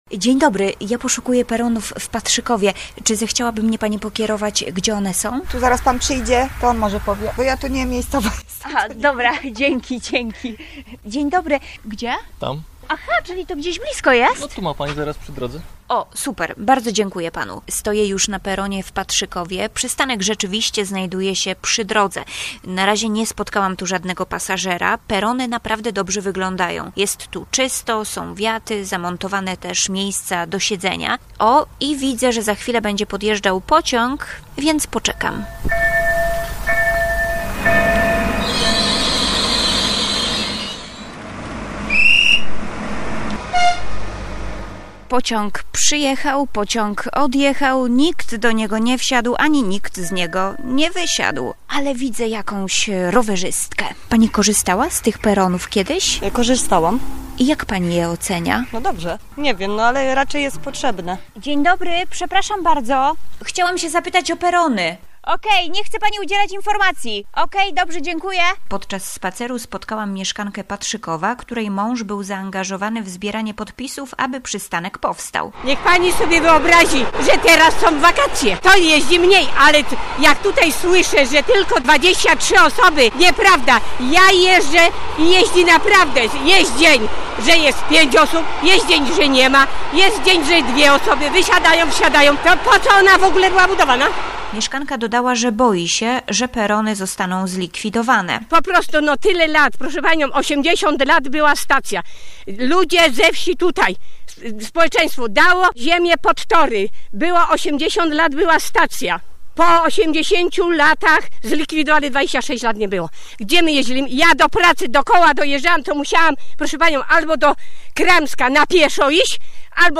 Jednak mieszkańcy, z którymi rozmawialiśmy, z inwestycji są zadowoleni.